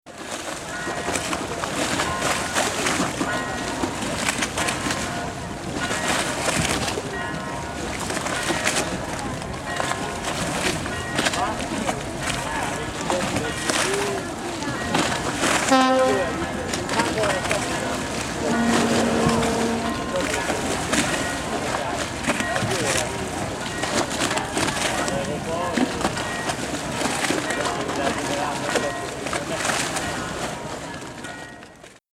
gondoles.mp3